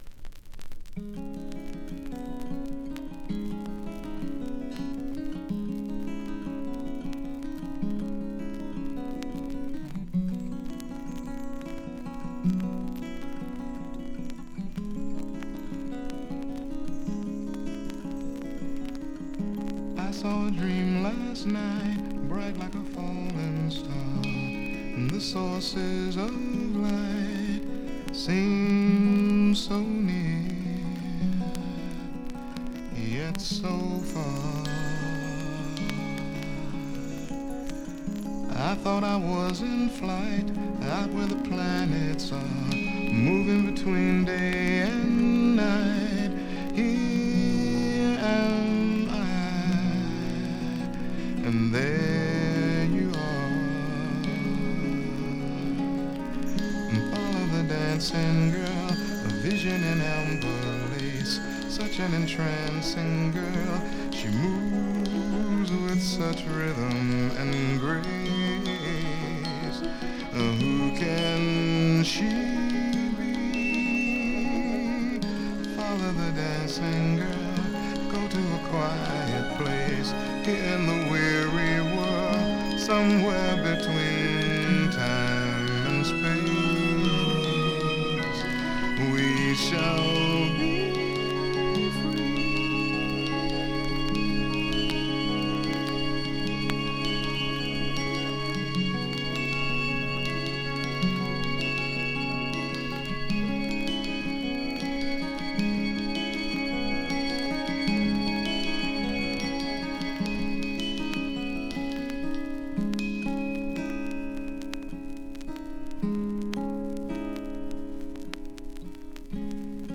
New Soul Classic!シカゴ出身のシンガー・ソングライター。
【SOUL】【FUNK】
Aサイド1曲目前半のノイズが目立ちますので試聴ファイルにてご確認下さい。